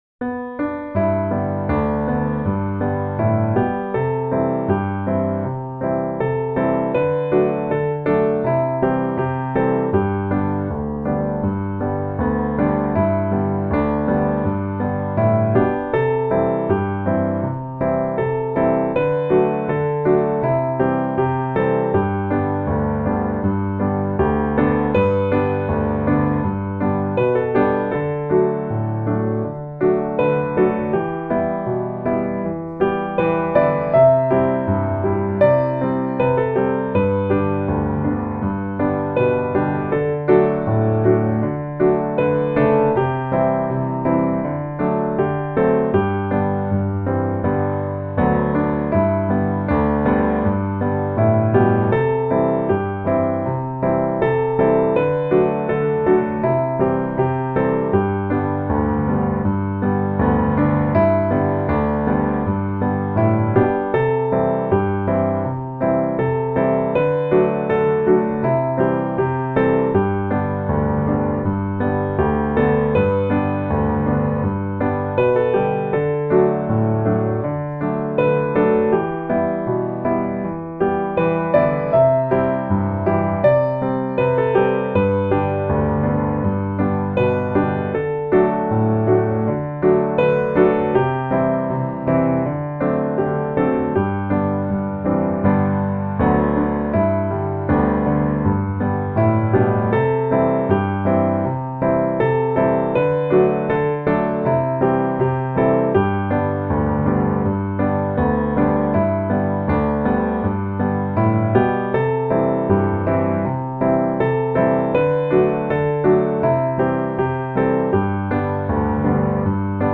G Majeur
Peculiar Meter.